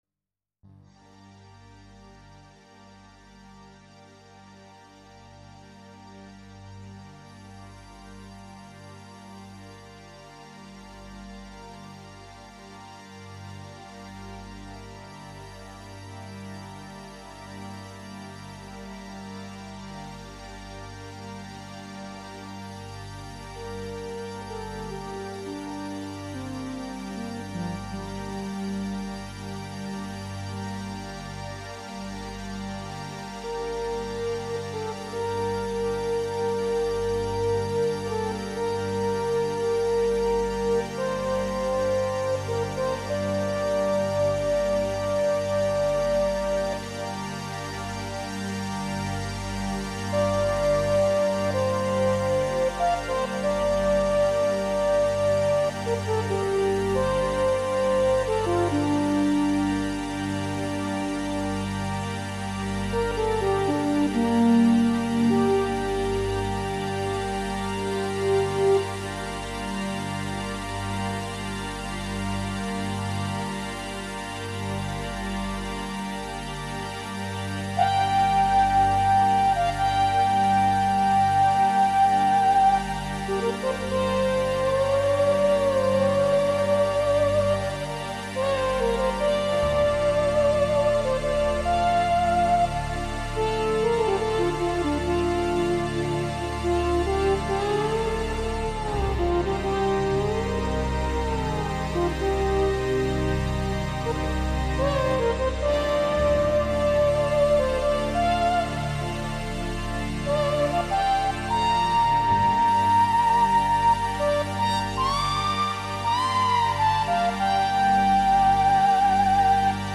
Well the original was, but this one is 12 bit digital.